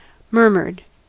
Ääntäminen
GA : IPA : /ˈmɝ.mɚd/